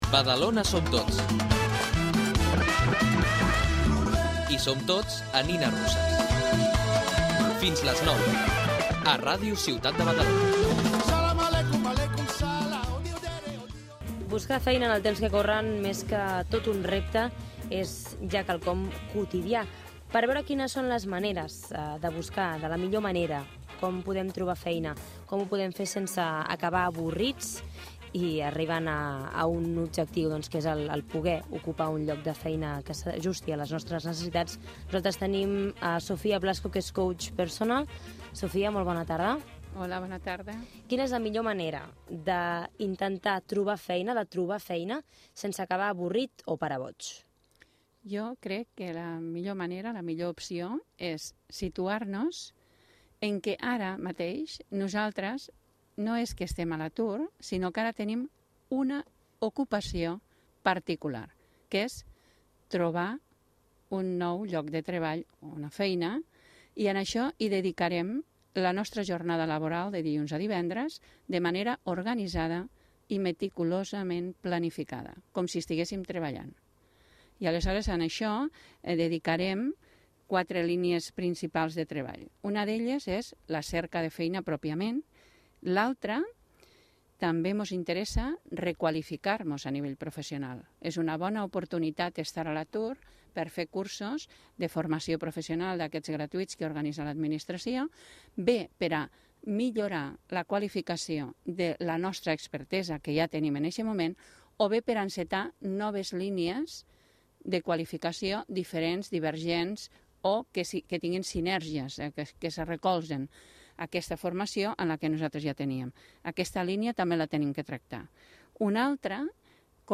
Al programa ‘Nines Russes’, de Ràdio Ciutat de Badalona, explicant com organitzar-nos per trobar una nova ocupació, si estem a l’atur.